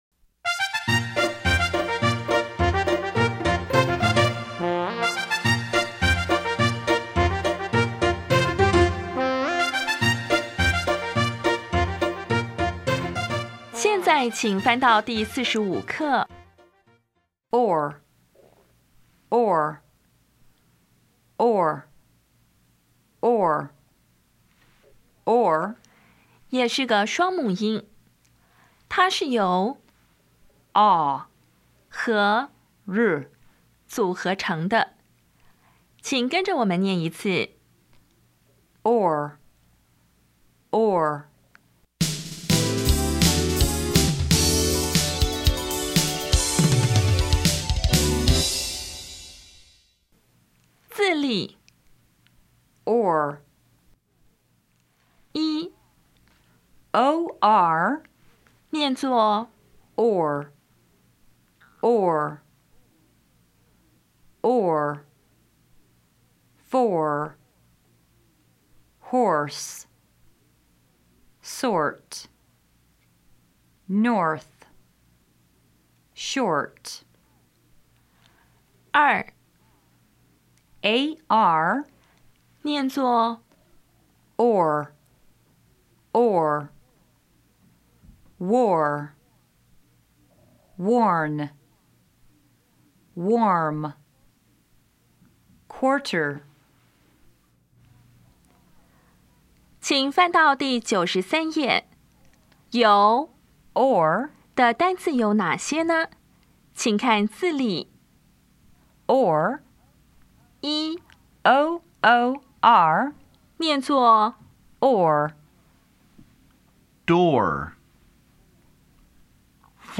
音标讲解第四十五课
[ɔr]  *形式上为[ɔr]，实际上念[ɔɚ]
[or]  *形式上为[or]，实际上念[oɚ]